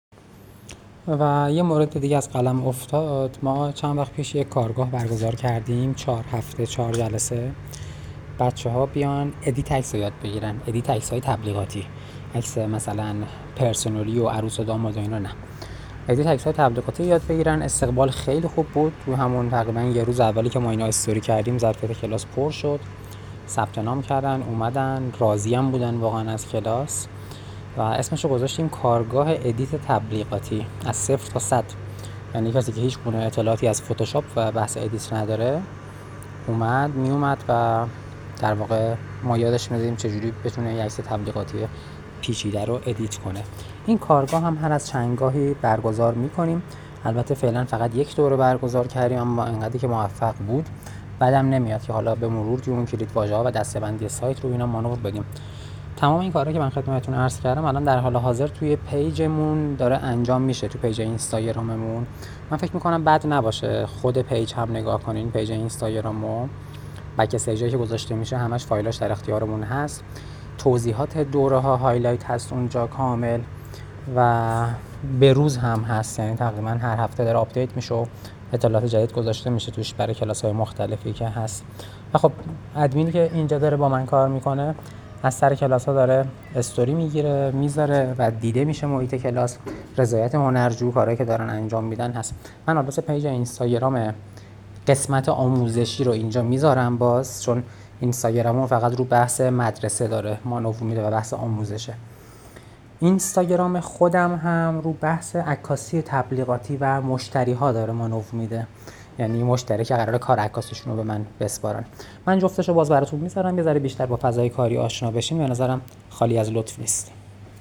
بخشی از رضایت دانشجویان دوره :